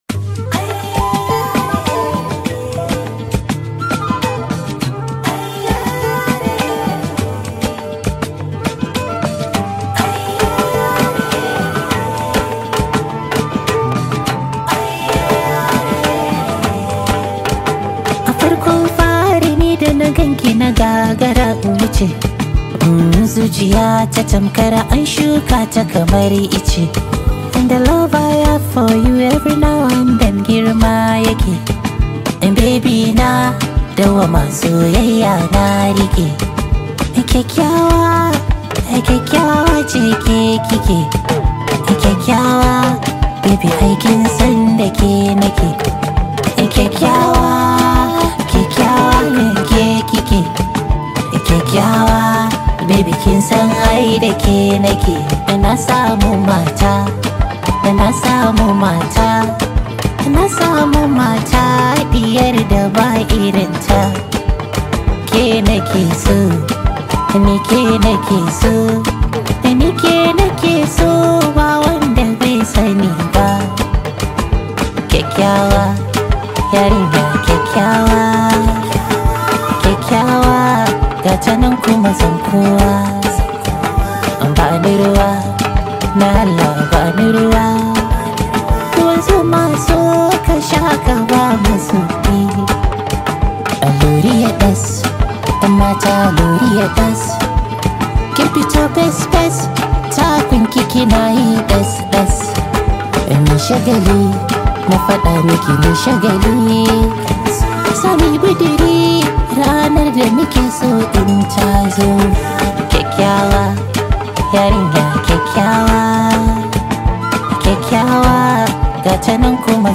top rated Nigerian Hausa Music artist
This high vibe hausa song